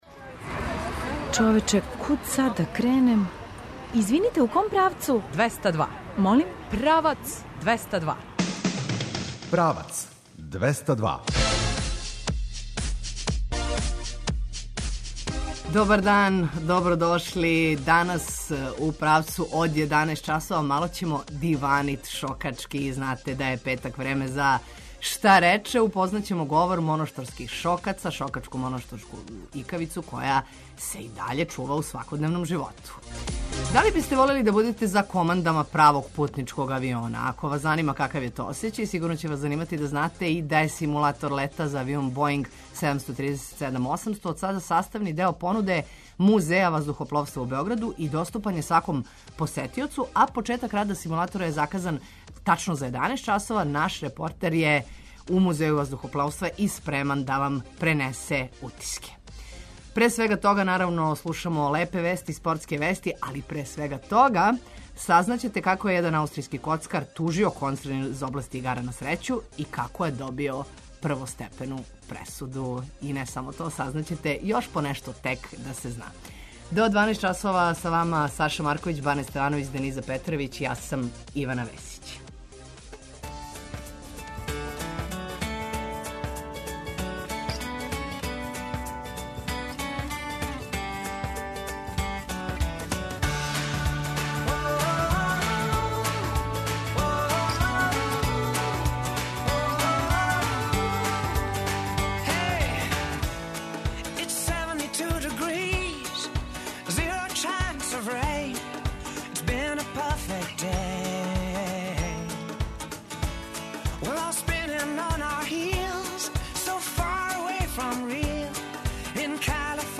Upoznaćemo govor monoštorskih Šokaca, šokačku, monoštorsku ikavicu koja se i dalje čuva u svakodnevnom životu. Jednom pesmom Monoštorke će nam poželeti i dobrodošlicu u biser Gornjeg Podunavlja, mada na šokačkom, kako kažu, pre nego ono dobro došli vole da pitaju pa di ste do sad :-)
Početak rada simulatora zakazan je za 11 časova a naš reporter je spreman da vam prenese utiske.